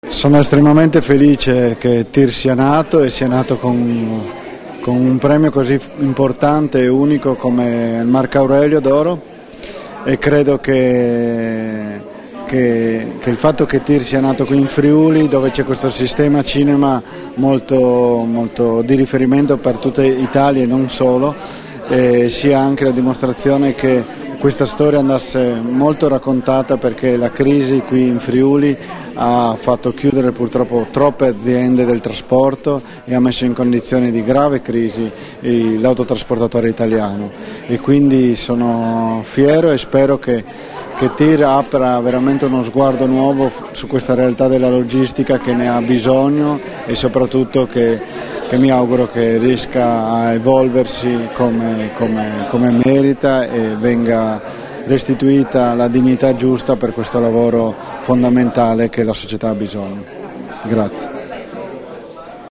alla conferenza stampa su "TIR", la sua pellicola che ha vinto il Marc'Aurelio d'Oro al Festival del Film di Roma, rilasciate a Trieste il 22 novembre 2013